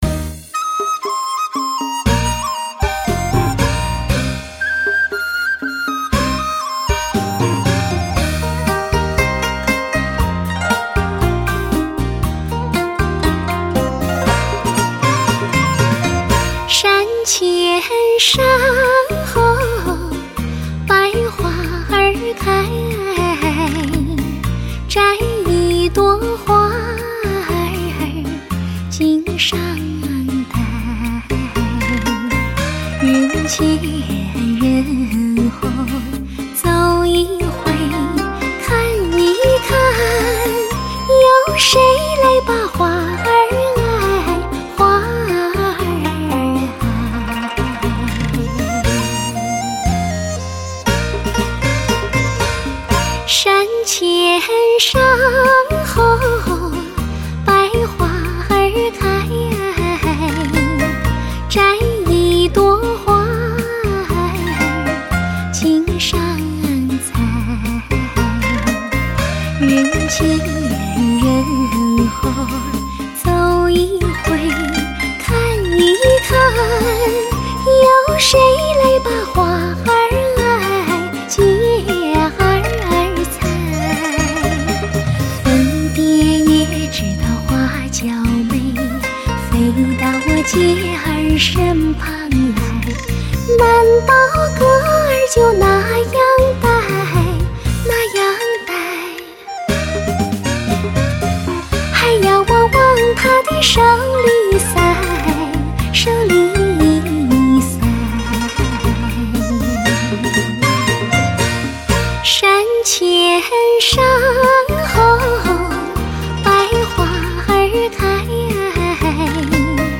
一尘不染的歌声似一弘添加了小许蜜糖的清澈泉水
清甜之中渗出令人回味无穷的清香雅韵
恍如静止的时光里 看似轻描淡写实则诗意盎然的演唱 令人悠然而思